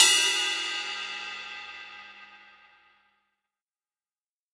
Crashes & Cymbals
20inchbell.wav